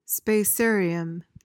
PRONUNCIATION:
(spay-SAYR-ee-uhm)